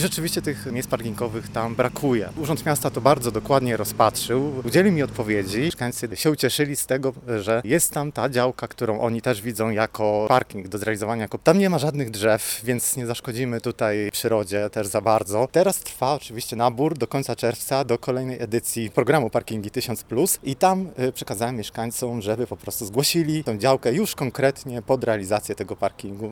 – Zgłosiłem interpelacje w tej sprawie i znalazło się odpowiednie miejsce – mówi Paweł Zalewski, radny miasta: